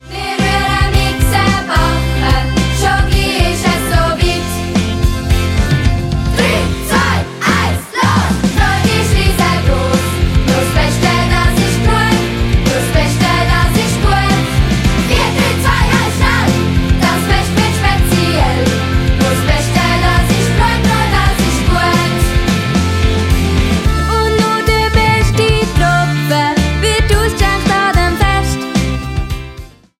Musical-Album